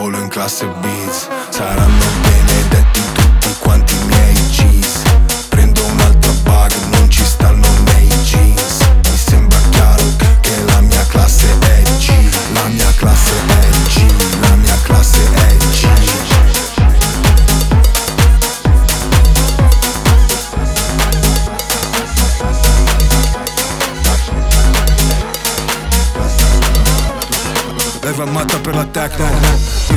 Модульные синтезаторы и глитчи трека
создают футуристичный саунд
2025-04-25 Жанр: Электроника Длительность